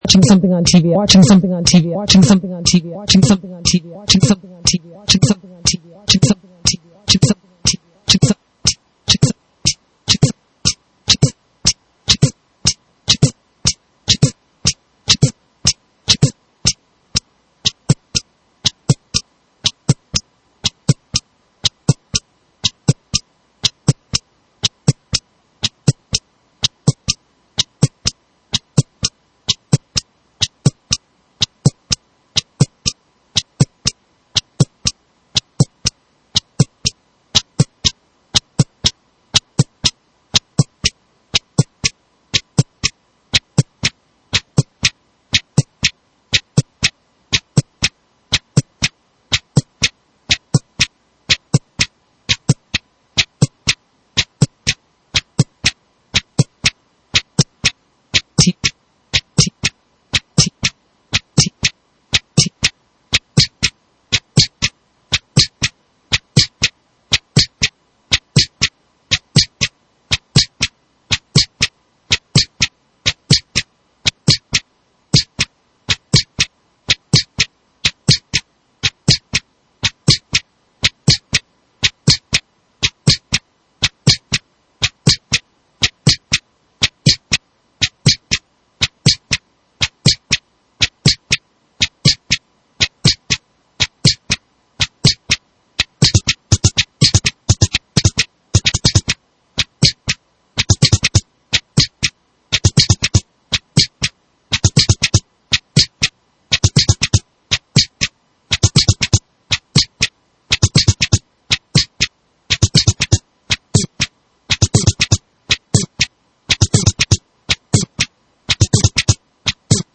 weekly show remixing NYC morning radio.